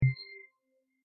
windowHide.mp3